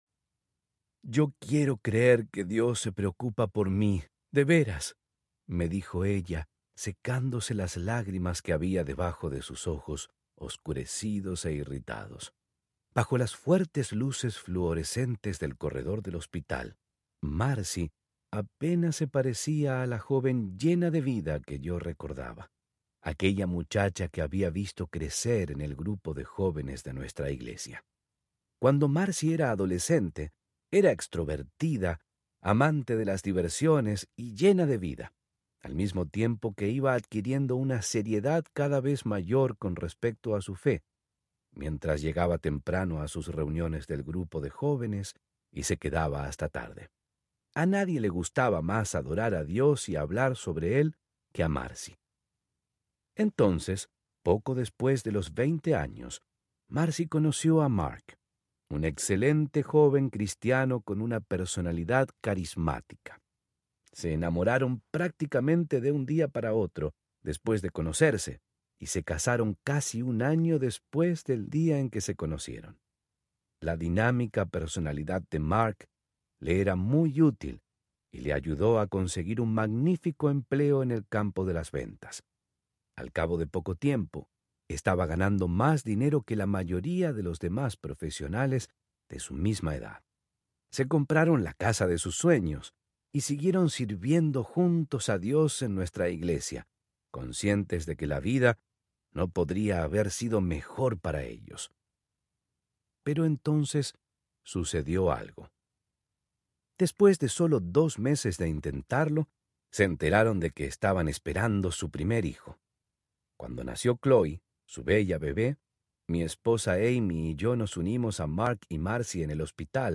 Esperanza en la oscuridad Audiobook
Narrator
5.1 Hrs. – Unabridged